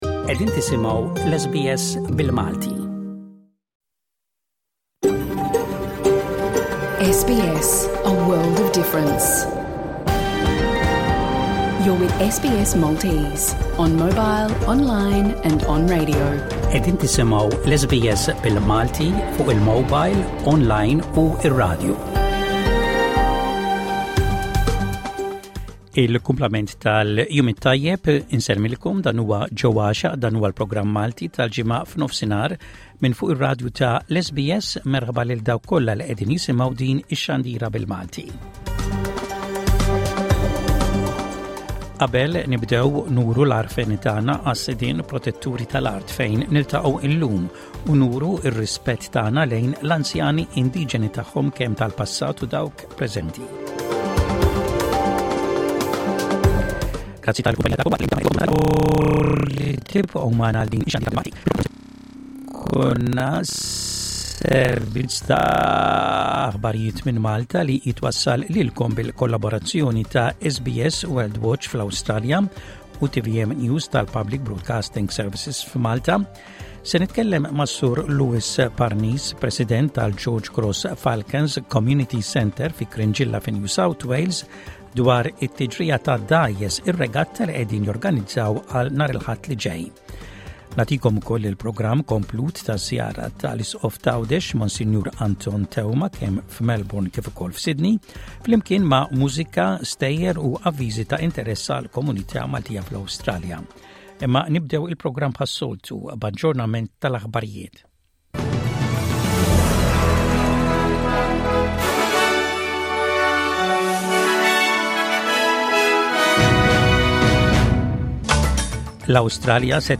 Intervista